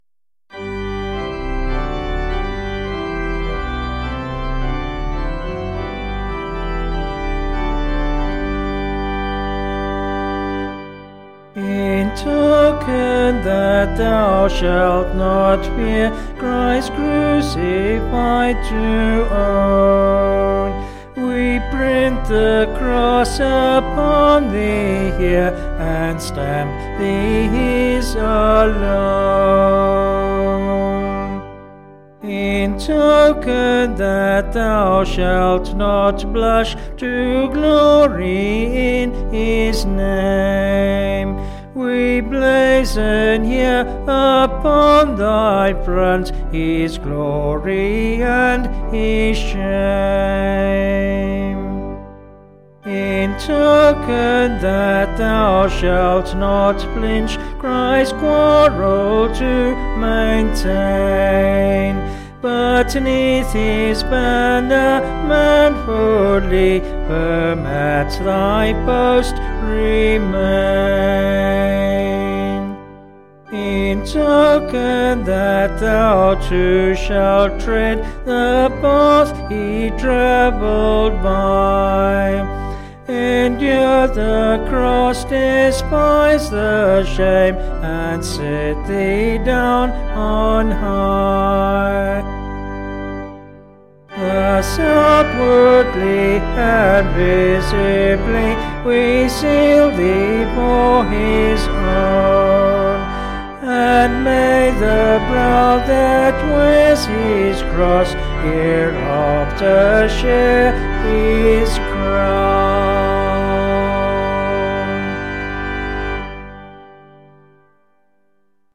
Vocals and Organ   265kb Sung Lyrics